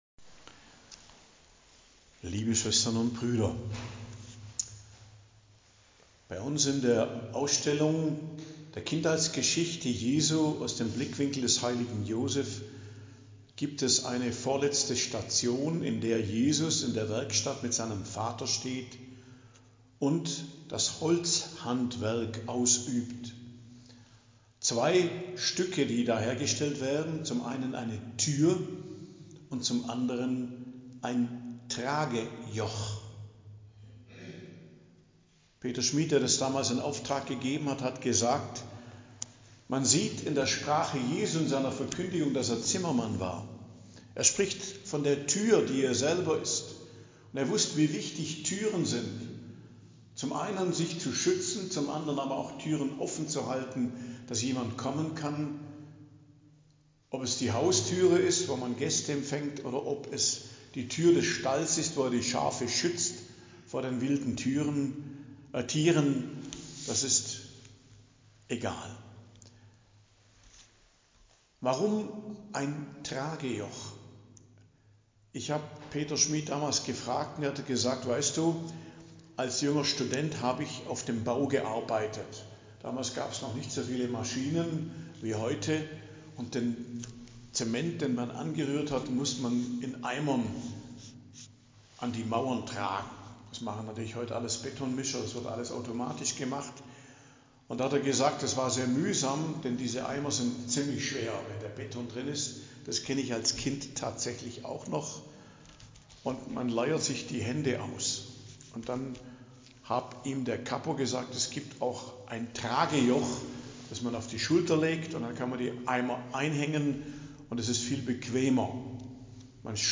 Predigt am Donnerstag der 15. Woche i.J., 17.07.2025